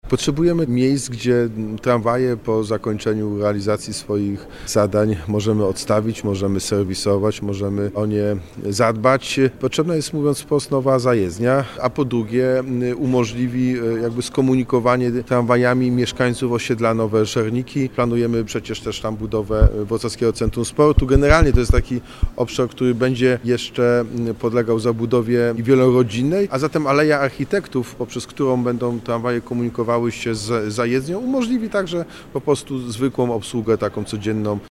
Prezydent Wrocławia Jacek Sutryk zwraca uwagę na rozbudowę systemu i obsługę ruchu pasażerskiego w mieście.